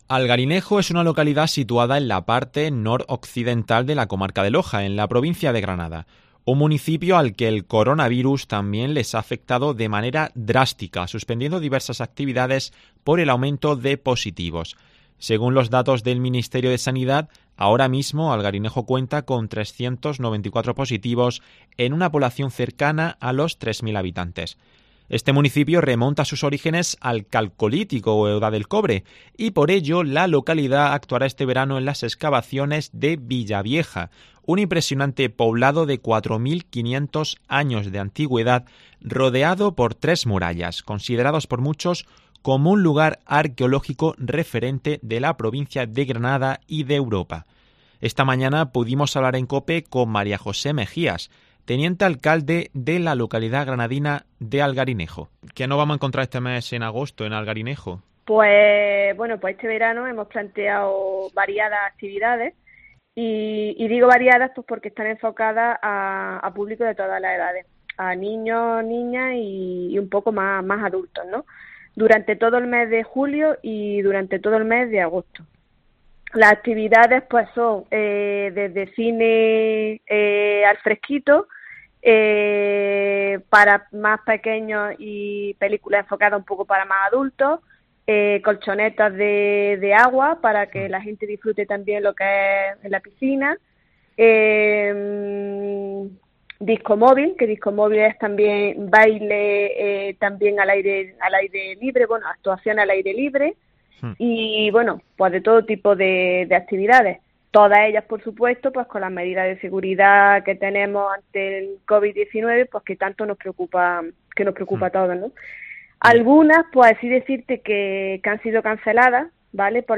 Esta mañana, pudimos hablar en COPE con María José Megías, teniente alcalde de la localidad granadina de Algarinejo.